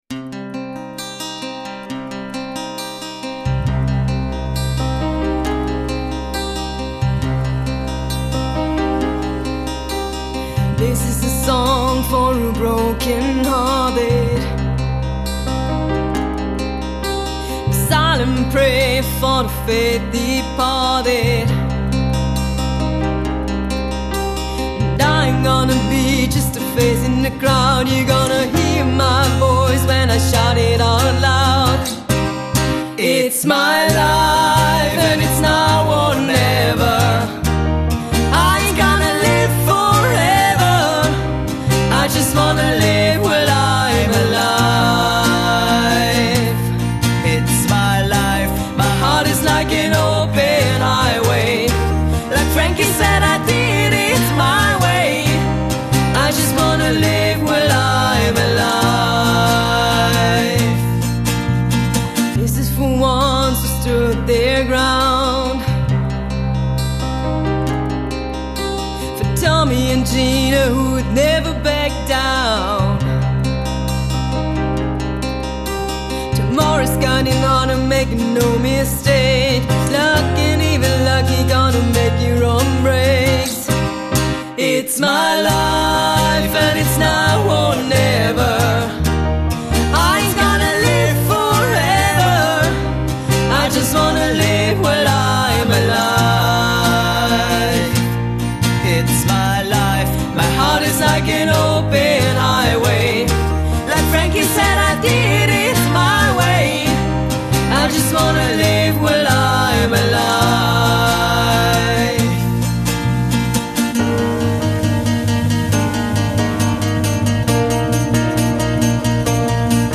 Cover-Trio aus Linz
(Cover-Genres: Pop/Classic-Rock/Oldies/Austro-/Deutsch-Pop)